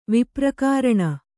♪ viprakāraṇa